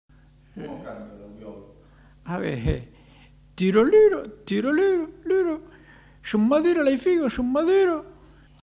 Mimologisme du loriot
Aire culturelle : Périgord
Genre : forme brève
Type de voix : voix d'homme
Production du son : récité
Classification : mimologisme